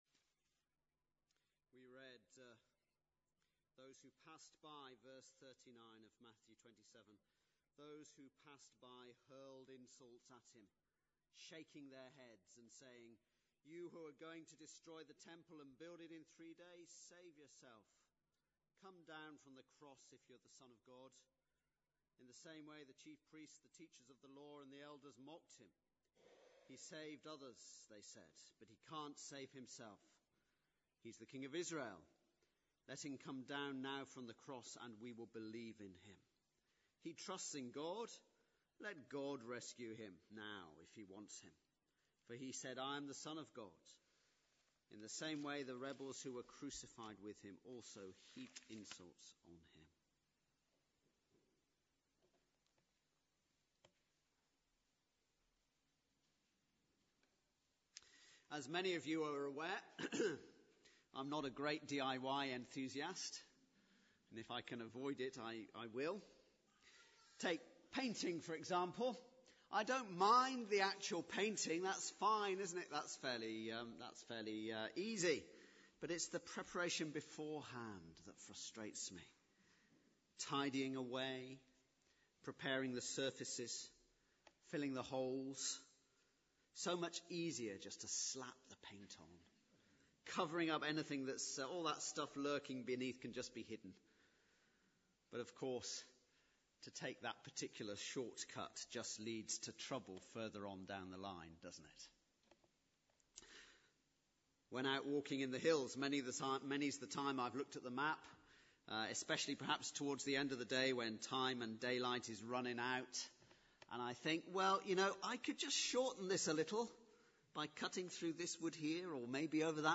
speaks on Good Friday 2014